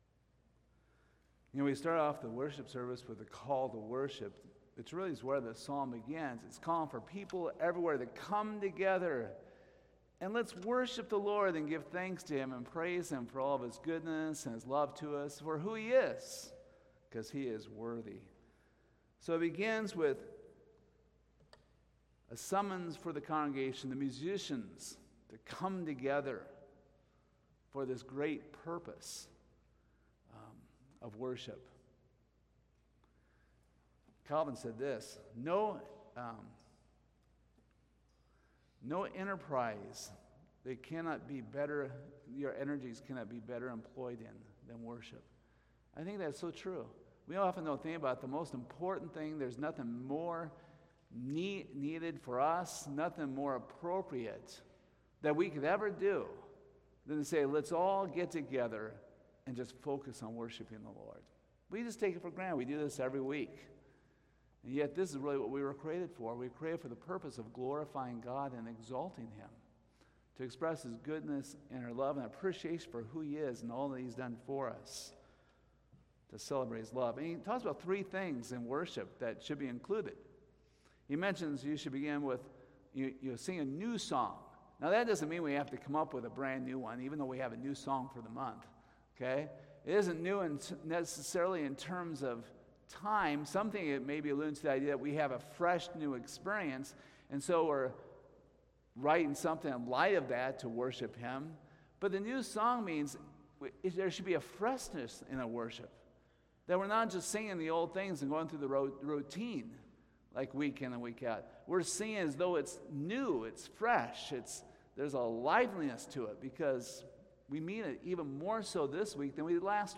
Service Type: Sunday Morning Topics: Creation , Praise , Thankfulness , Trust , Worship